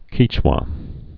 (kēchwə, -wä)